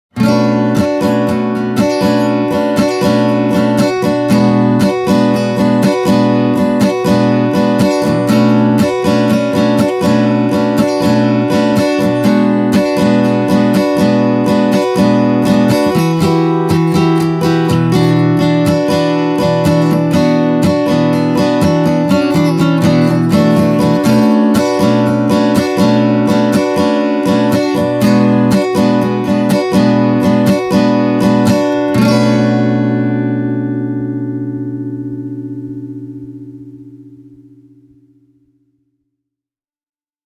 Walden’s CD4040-CERT is a beautiful-looking and -sounding guitar – and it’s also quite loud. The bottom end is fat and muscular, without ever mushing out, while the midrange is vocal and clear.
Walden CD4040-CERT – plectrum